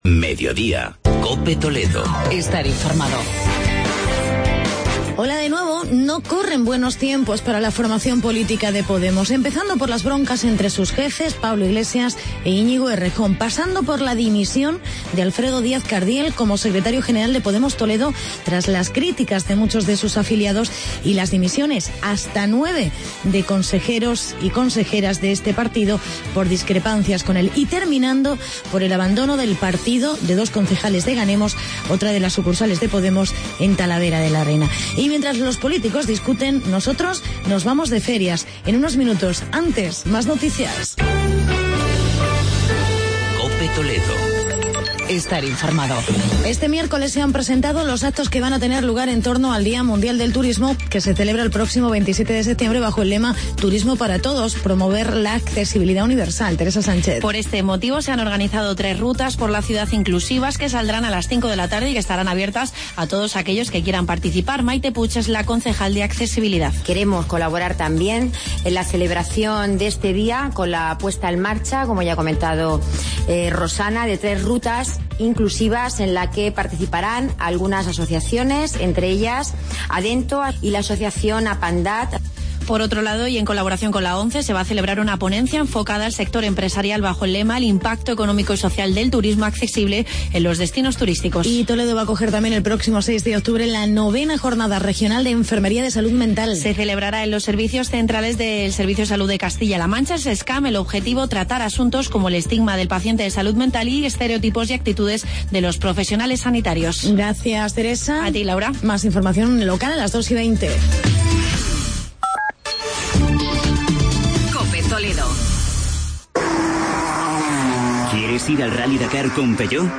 Actualidad y entrevista con el concejal de Festejos, José Luis Muelas, sobre las Ferias de San Mateo de Talavera de la Reina.